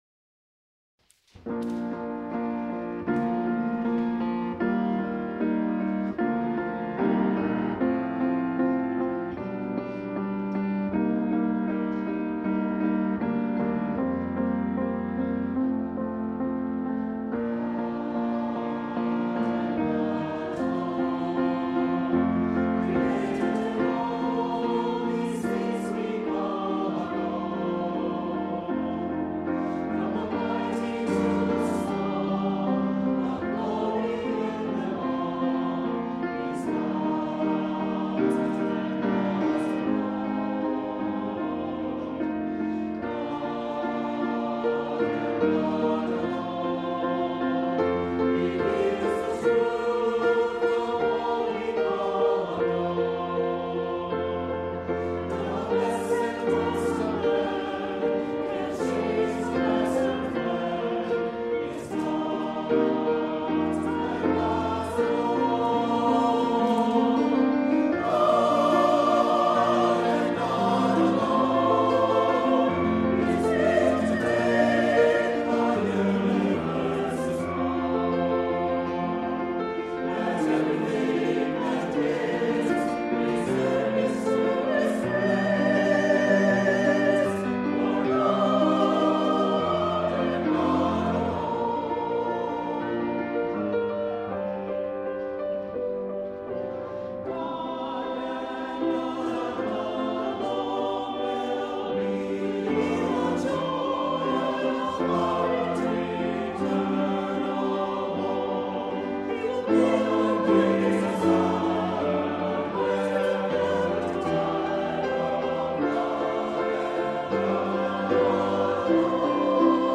Here are the recordings of our Chancel Choir and Bell Choir as featured in our unpublished Joyful Sounds, Volume 2 CD.